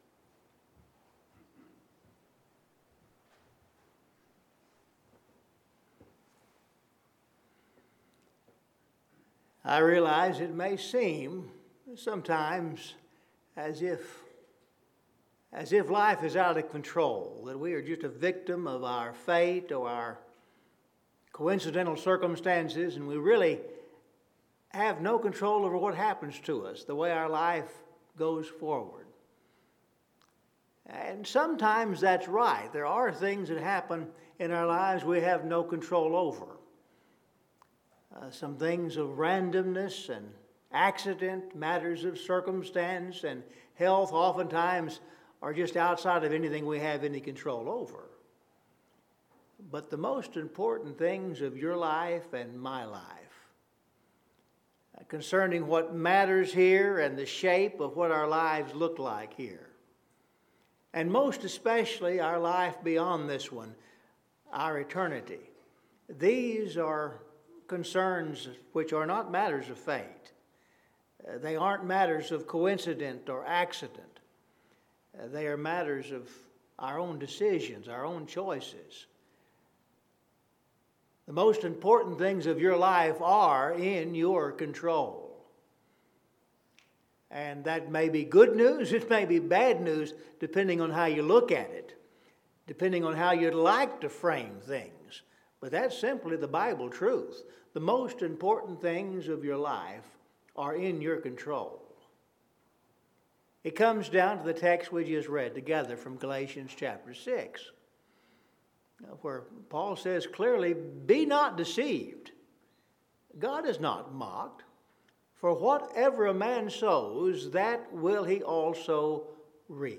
Scripture Reading – Galatians 6:7-10